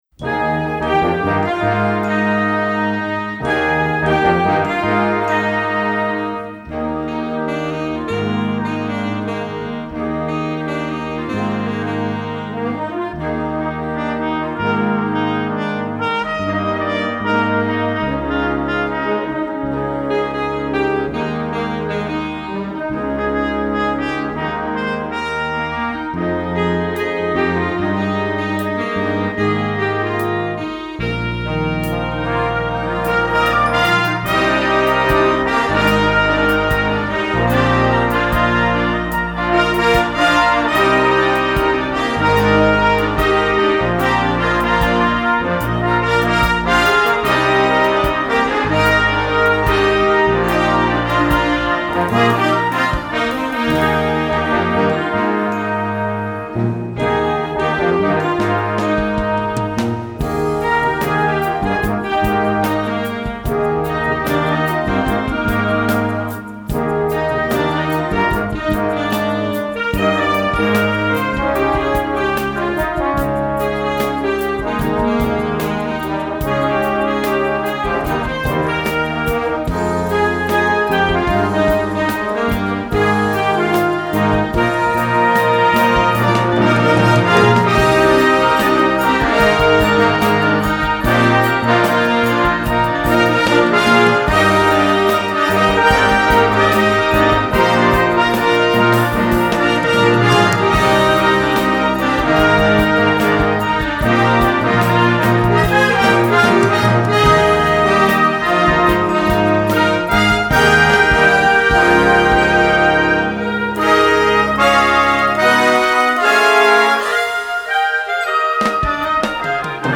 Gattung: Moderner Einzeltitel
Besetzung: Blasorchester
Die schwungvolle Bearbeitung einer atemberaubenden Nummer